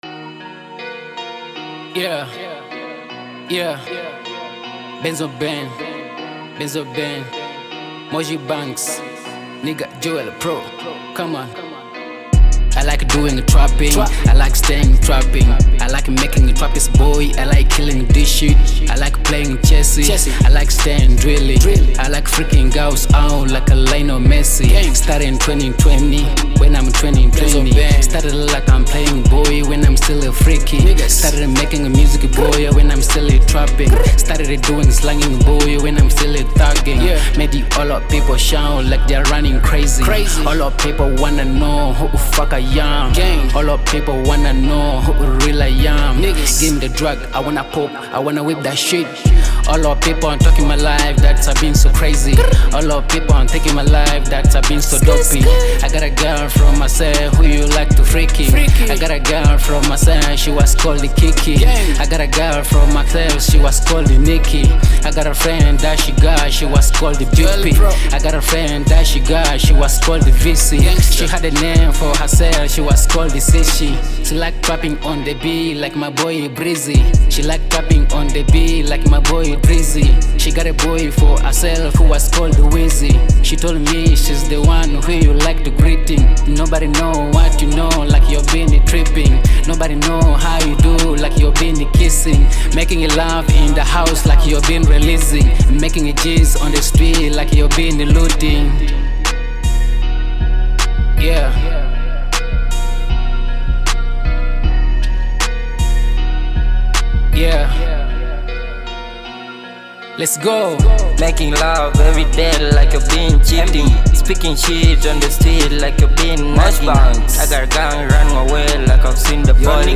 a wild rap anthem with heavy beats and bold bars